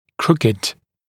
[‘krukɪd][‘крукид]кривой, изогнутый, искривленный